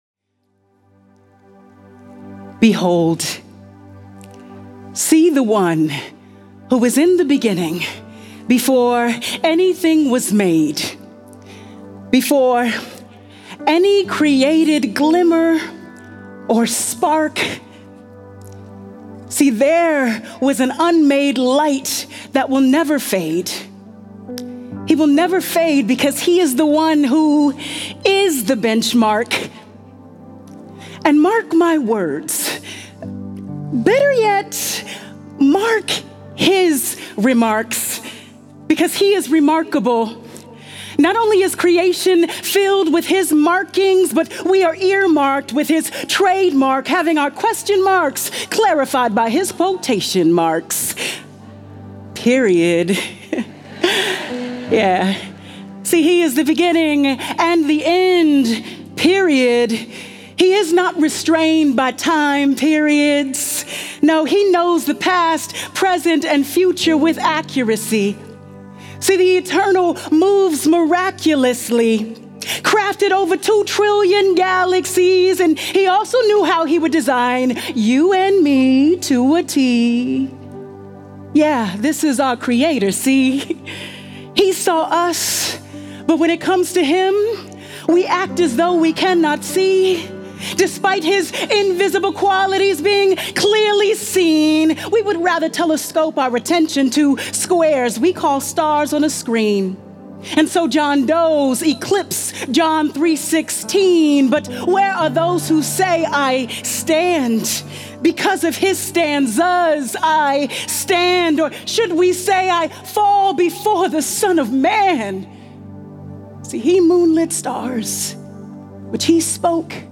Behold and Believe | True Woman '25 | Events | Revive Our Hearts